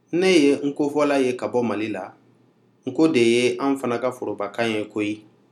This is a dialogue of people speaking Nko as their primary language.